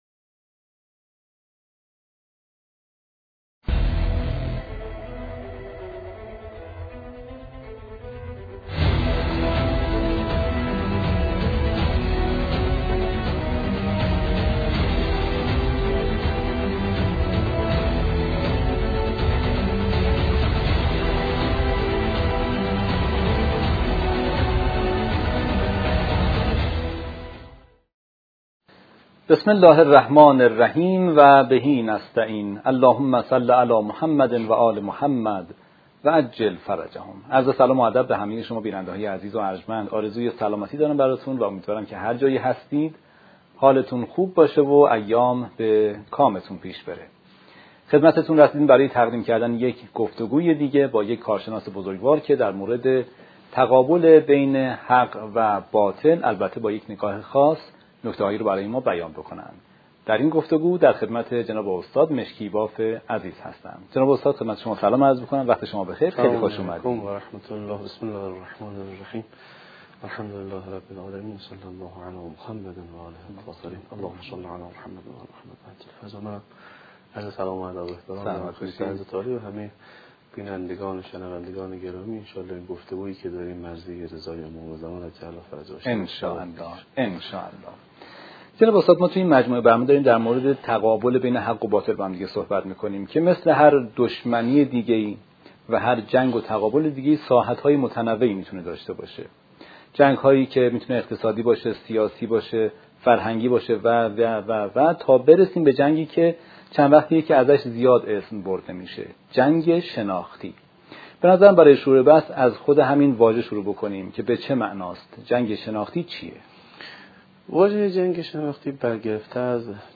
مقدمه و سلام‌پردازی مجری برنامه با ذکر صلوات و سلام، به بینندگان خوشامد می‌گوید و هدف از گفت‌وگو را بررسی تقابل حق و باطل با نگاهی ویژه بیان می‌کند. 2.